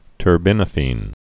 (tər-bĭnə-fēn, tûrbĭ-nə-fēn)